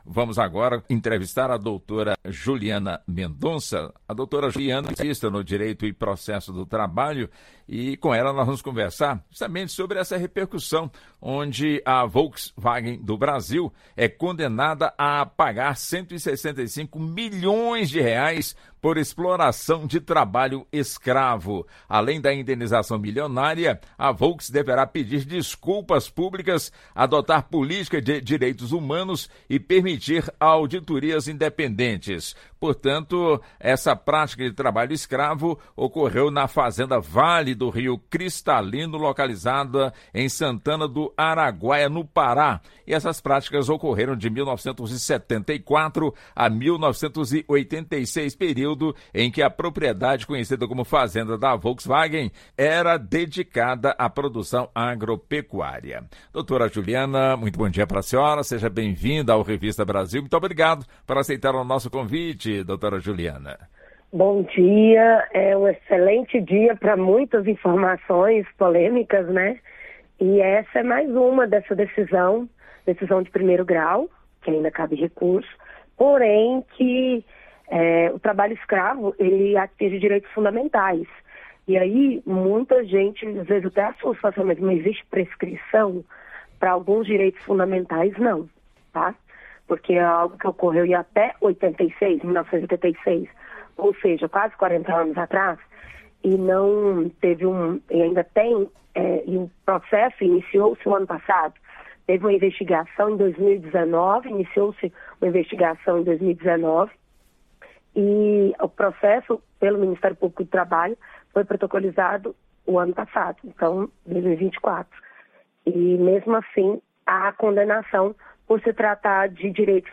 Advogada.